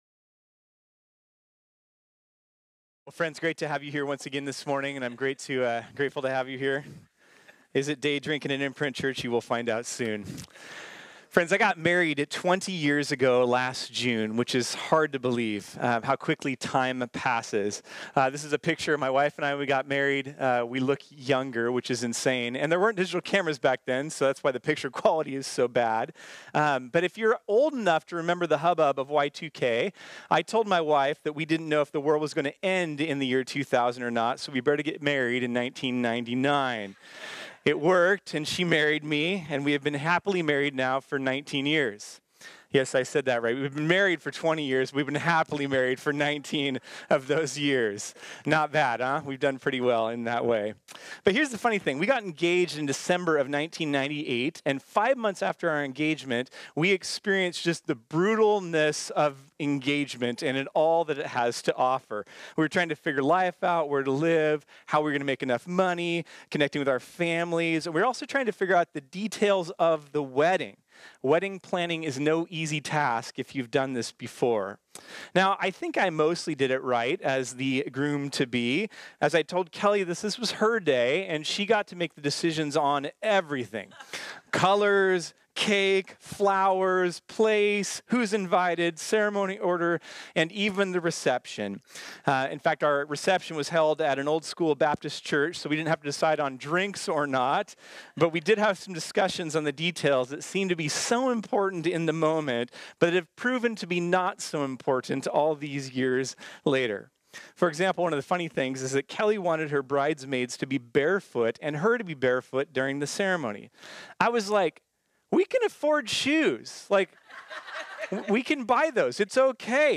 This sermon was originally preached on Sunday, September 29, 2019.